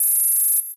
forcefield2.ogg